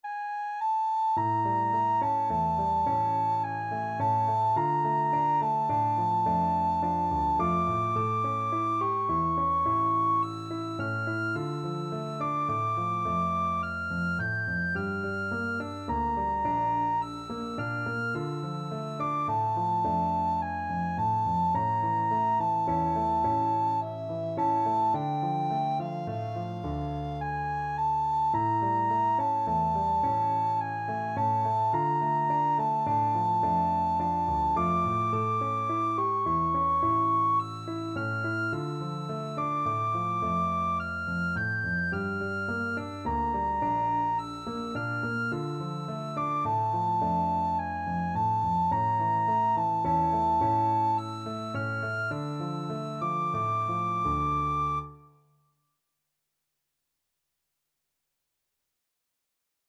Free Sheet music for Soprano (Descant) Recorder
Recorder
D minor (Sounding Pitch) (View more D minor Music for Recorder )
3/4 (View more 3/4 Music)
Slow Waltz = c. 106